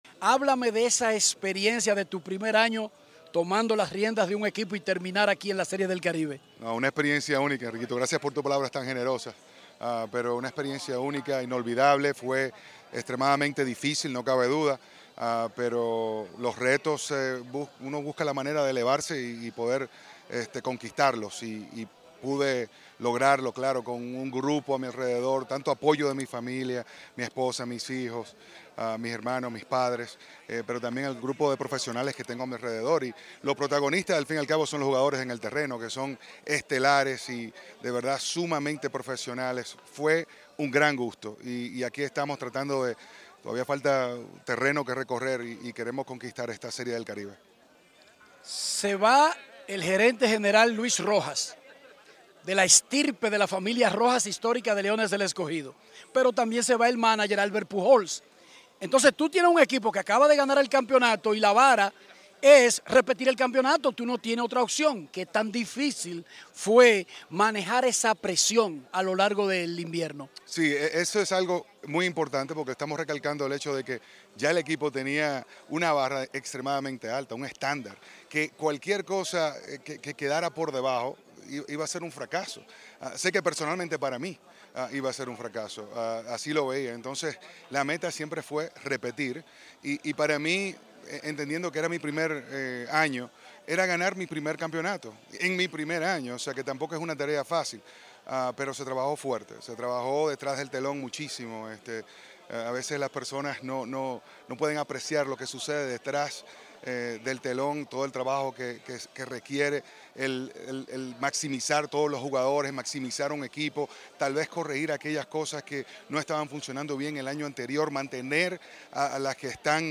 “Una experiencia única, inolvidable, fue extremadamente difícil” dijo Carlos Peña al ser entrevistado para Grandes en los Deportes, Web, Radio y Televisión.